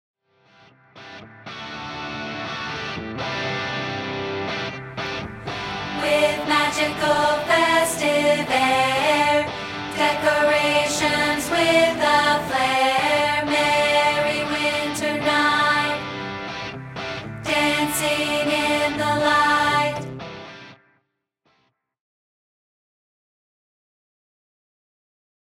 This is a rehearsal track of part 2, isolated